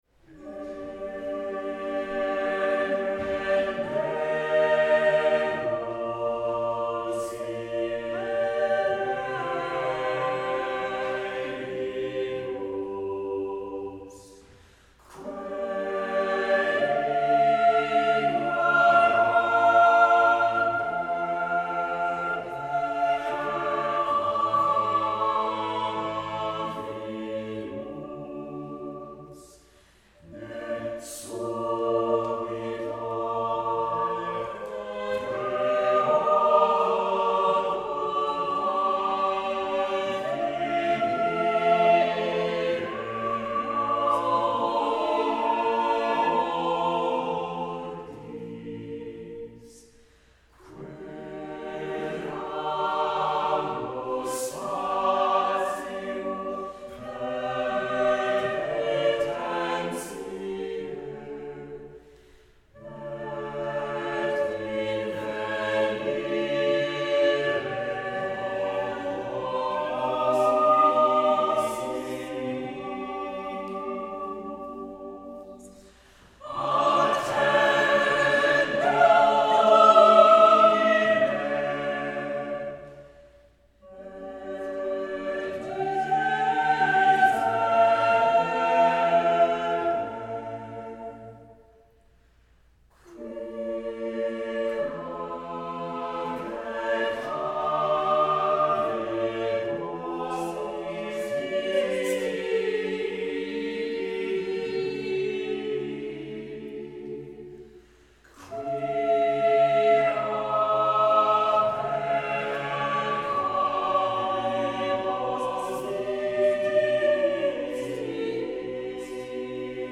SATTB
Music Category:      Choral